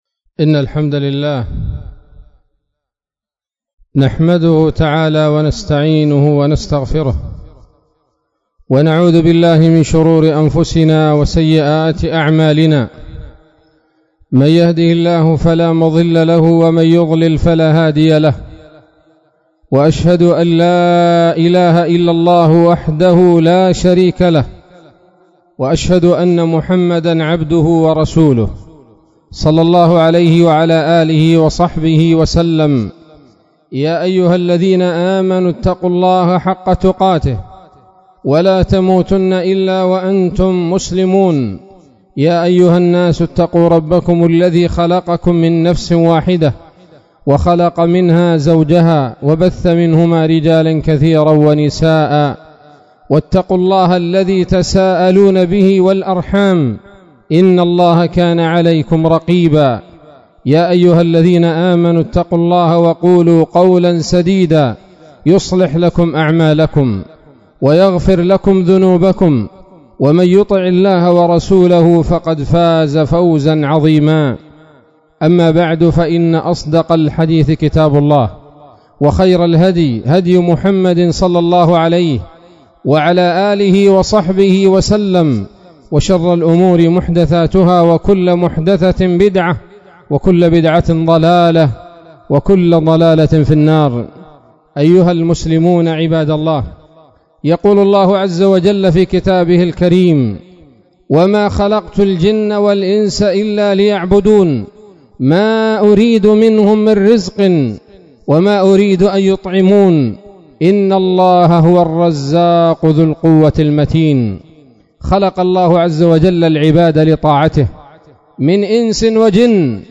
خطبة جمعة بعنوان: (( الإستعداد ليوم الرحيل )) 26 رجب 1444 هـ، بالجامع الكبير بصلاح الدين